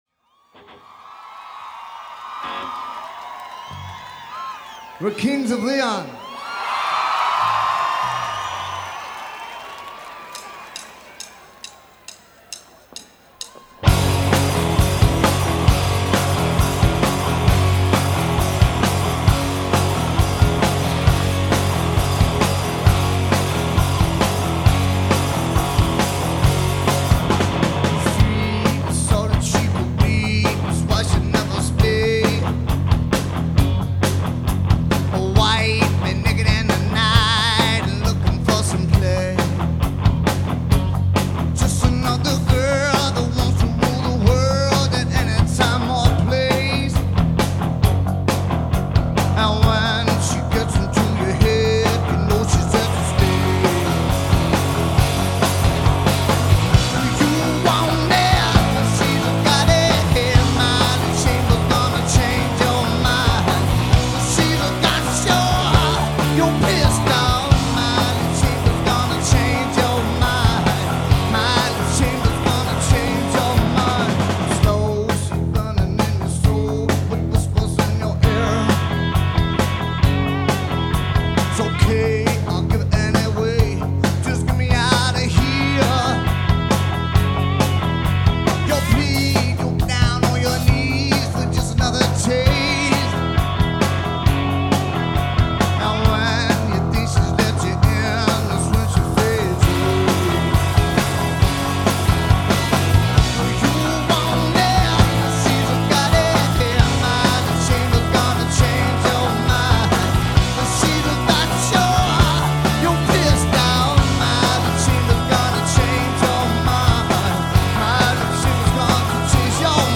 recorded in Amsterdam 2009